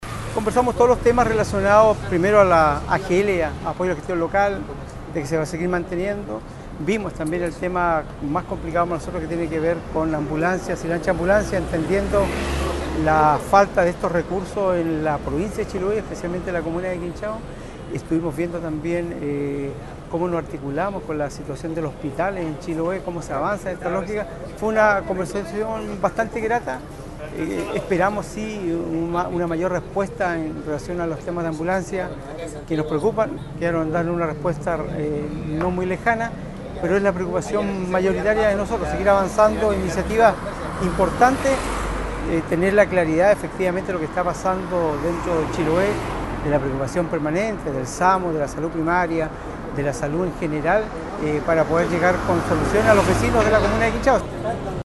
Asimismo, desde su propia realidad, el alcalde de Quinchao René Garcés manifestó que pudieron describir al subsecretario la serie de requerimientos de la atención de salud en las islas interiores de Chiloé.